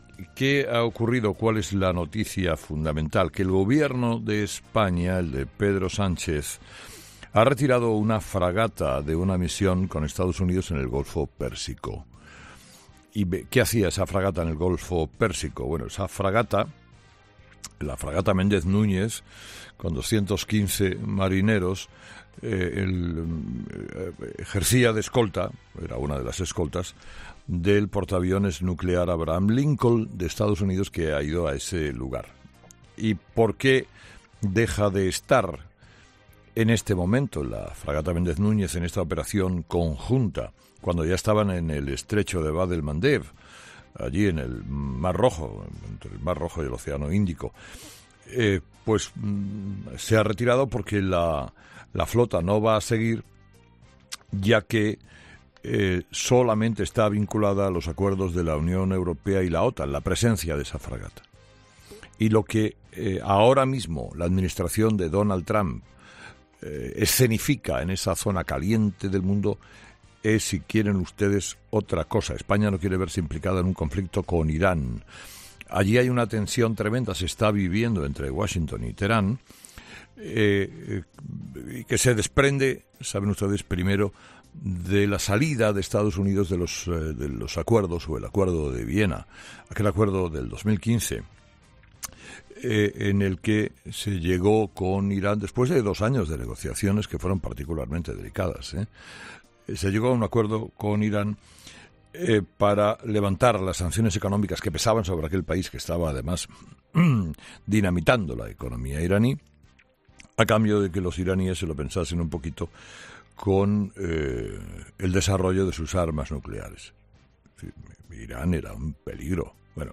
“La noticia fundamental es que el Gobierno de Sánchez ha retirado una fragata española en el Golfo Pérsico. La fragata Méndez Núñez, con 215 marineros, ejercía de escolta del portaaviones militar Abraham Lincoln en la zona", así ha iniciado Carlos Herrera su monólogo de las seis de la mañana.